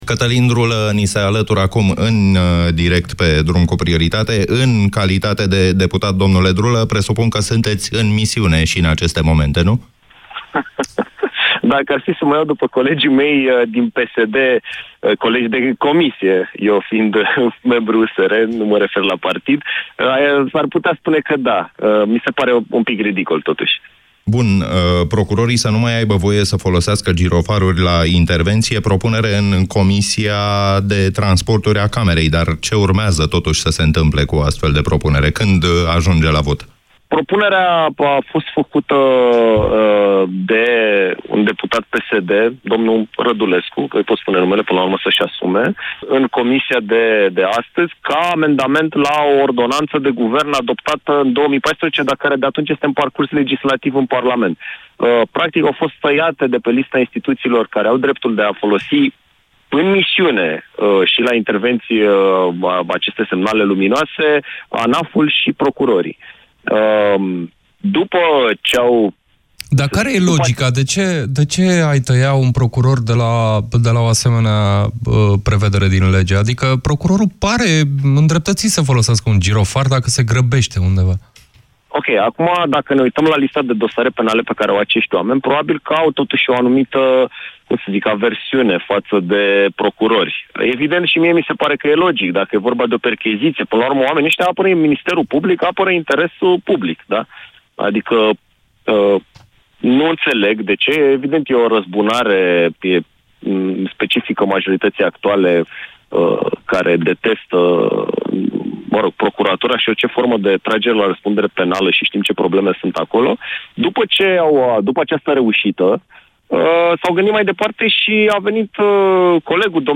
Deputatul USR a explicat în emisiunea Drum cu prioritate care au fost argumentele parlamentarilor pentru aprobarea acestui vot.